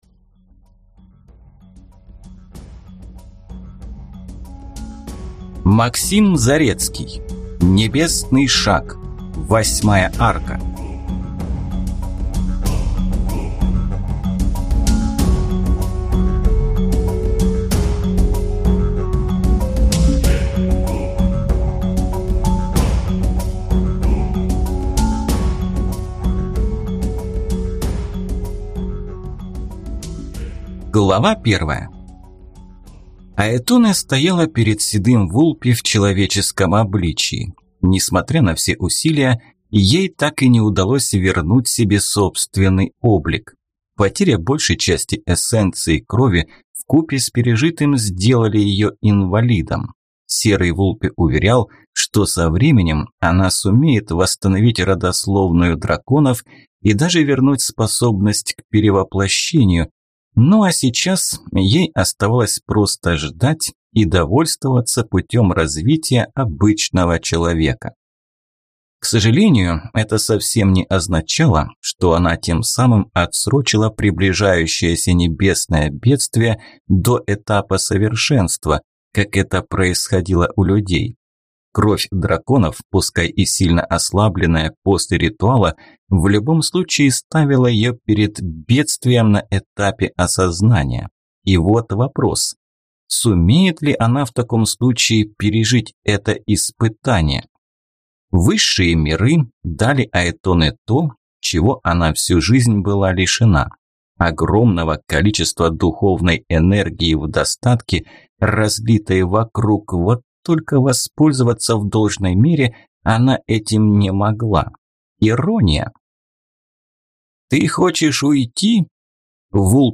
Аудиокнига Небесный шаг (8 арка) | Библиотека аудиокниг